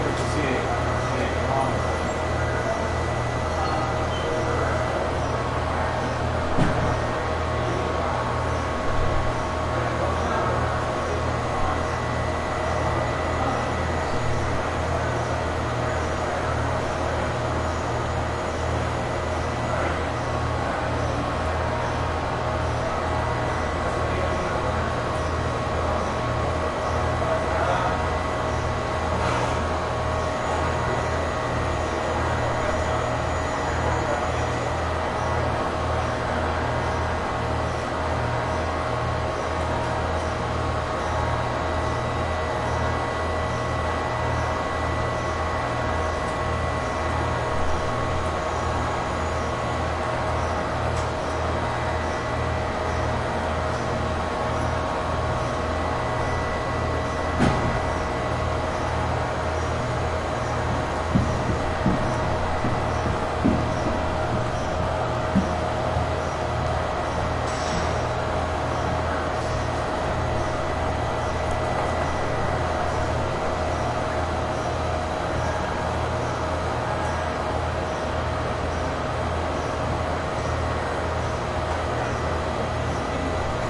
工业 " 铸造炉
描述：来自一个实际的铸造厂的录音。质量并不惊人。
标签： 铸造 工业 金属 车间
声道立体声